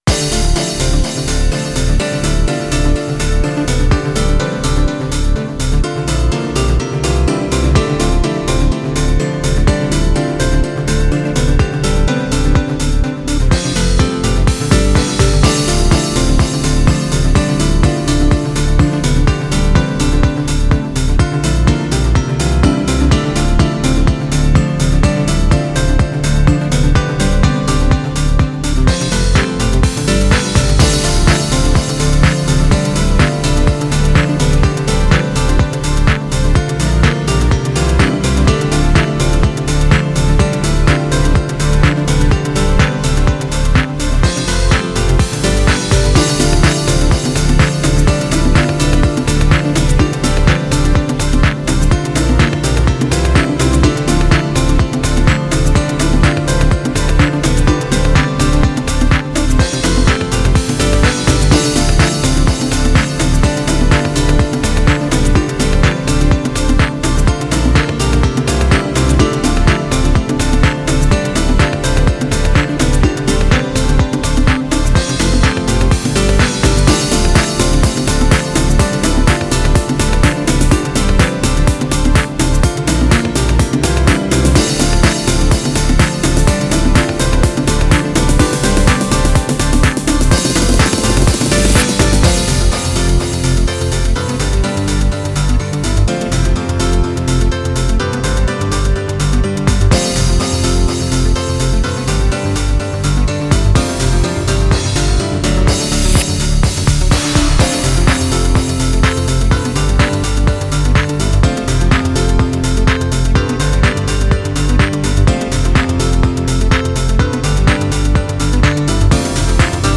HOUSE（BPM１２５）
イメージ：宇宙　ジャンル：Dream House、Piano House